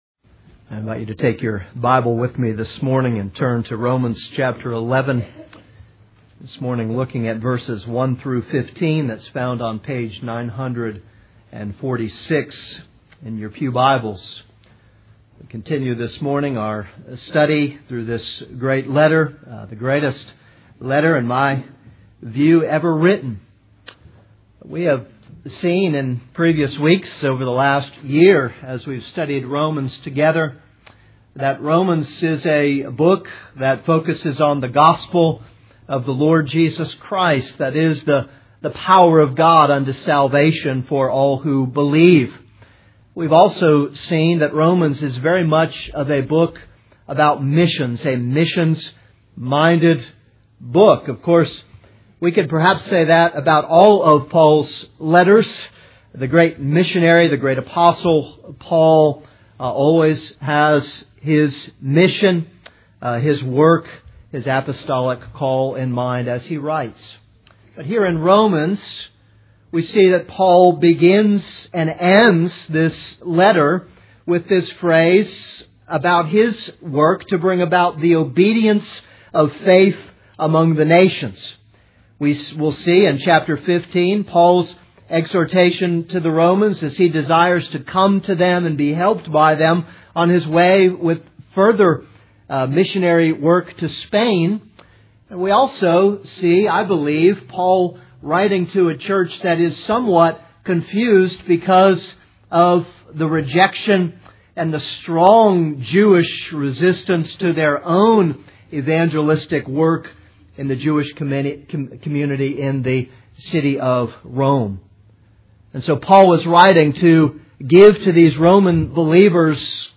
This is a sermon on Romans 11:1-15.